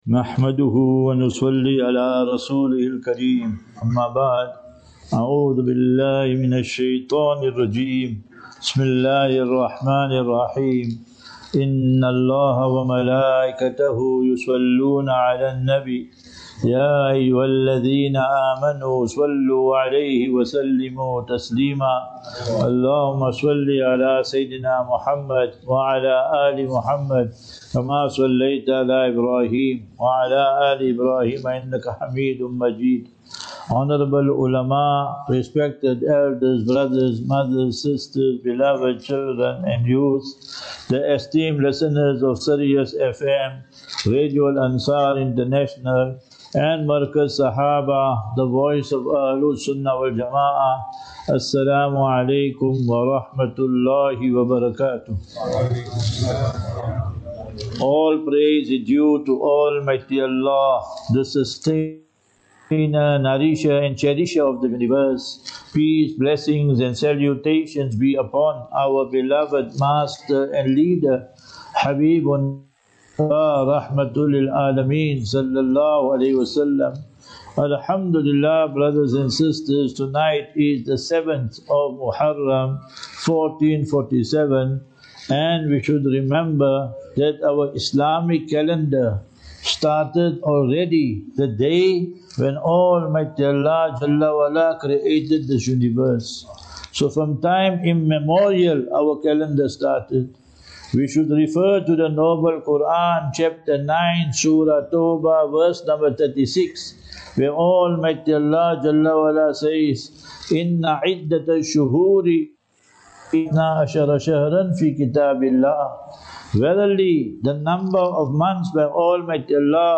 2 Jul 02 July 2025 - Muharram Lecture Program
Lectures